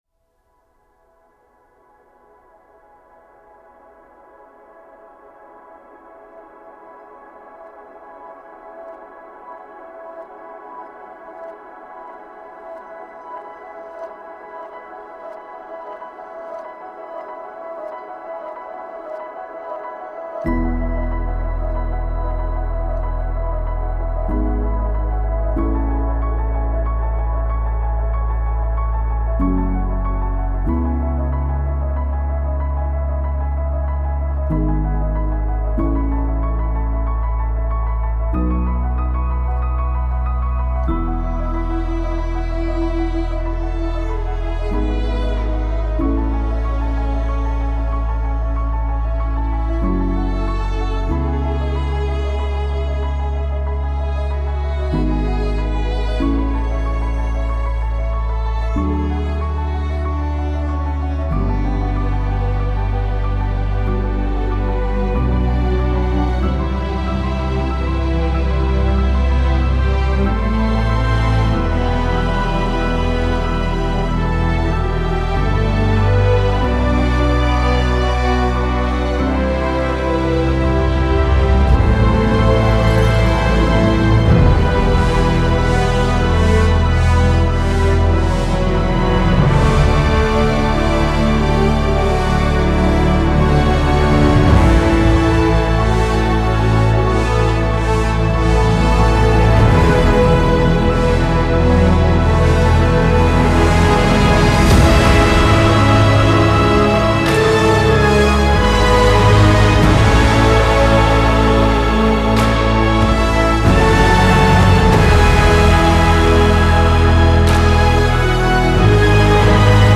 اپیک ارکسترال الهام‌بخش باشکوه موسیقی بی کلام